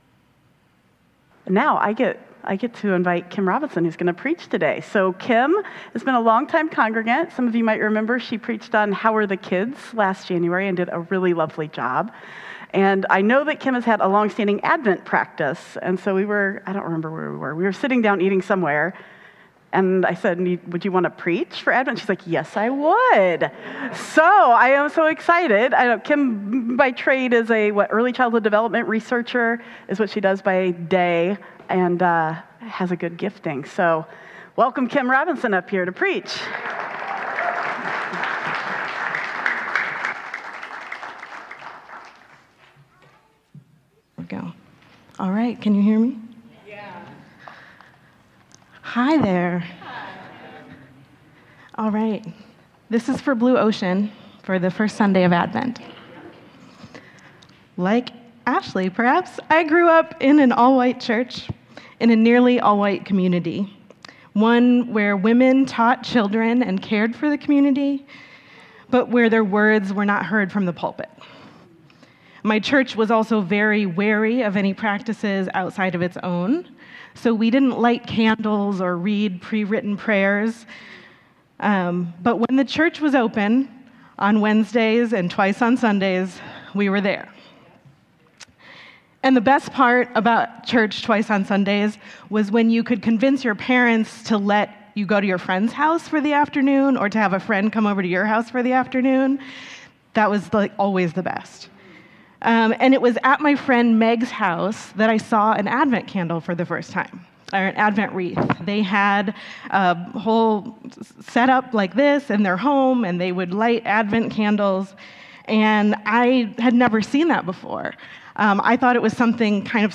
Advent sermon series